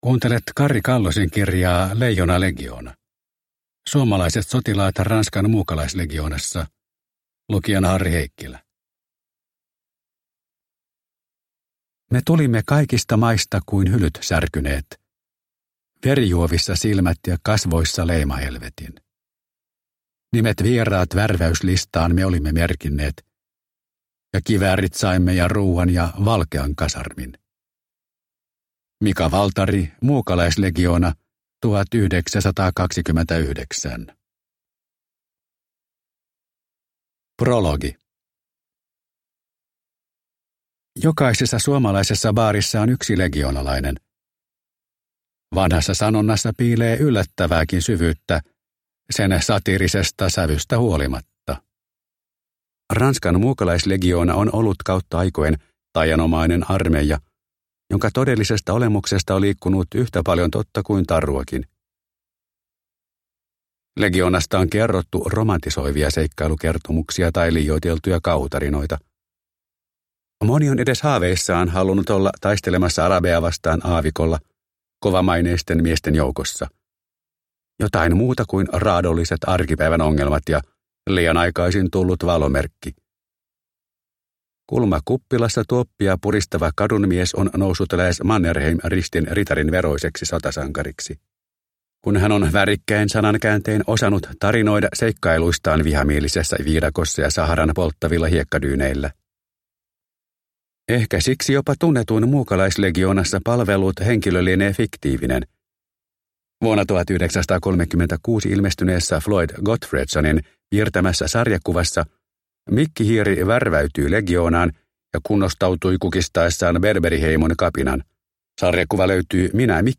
Leijonalegioona – Ljudbok – Laddas ner